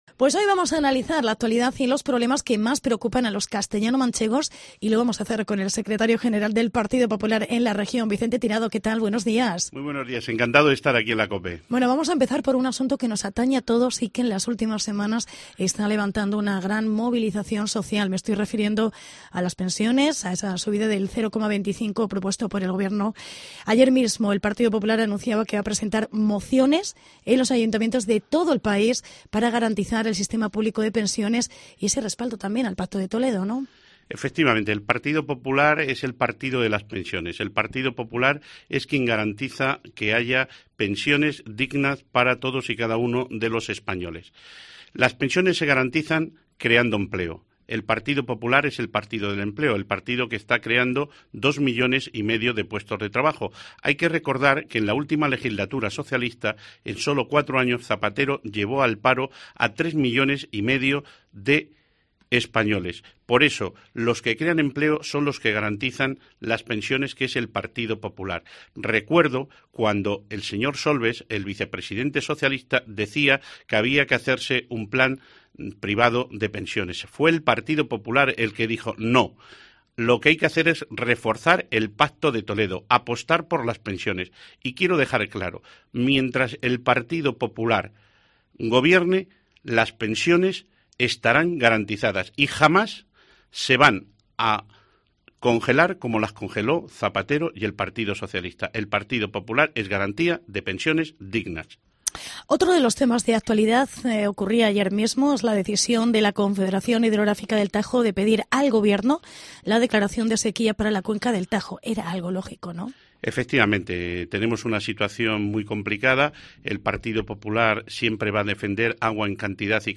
Entrevista con Vicente Tirado